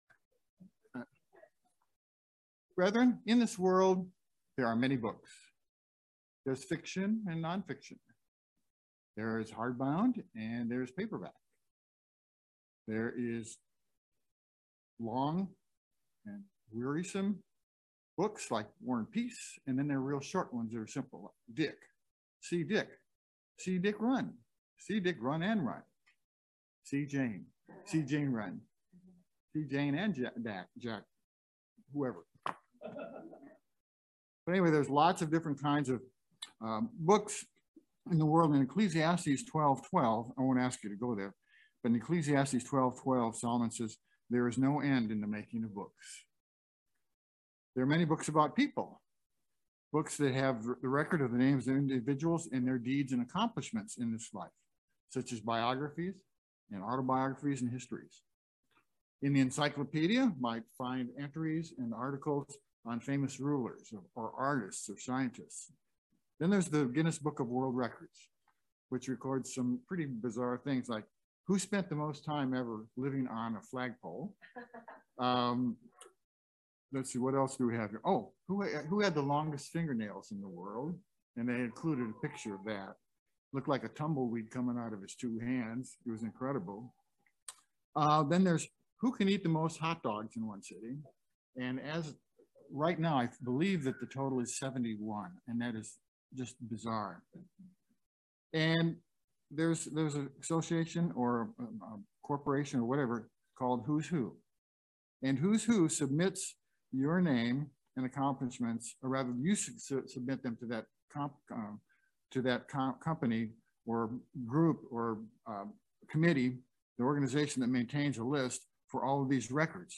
Eye opening sermon on the subject of the book of life. Do you understand what it is , and what it takes to have your name written in the book of life?